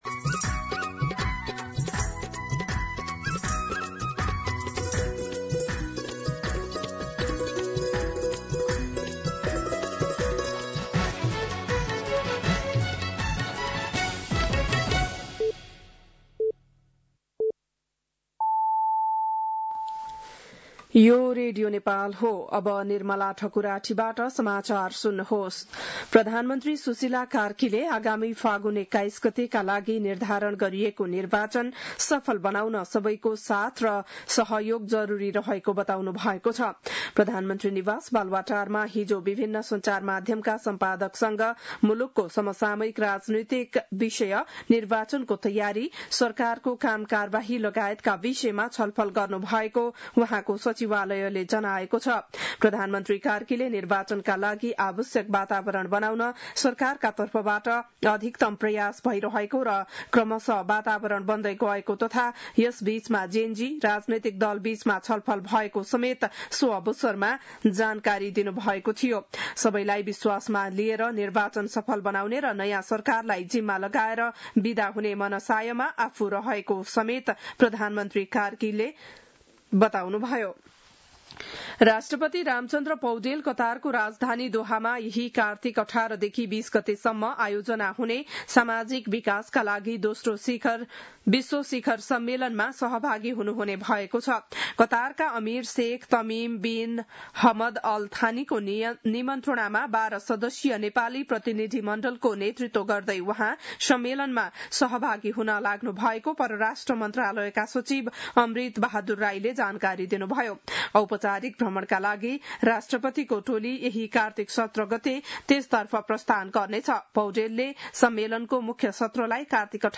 बिहान ११ बजेको नेपाली समाचार : १५ कार्तिक , २०८२
11-am-Nepali-News.mp3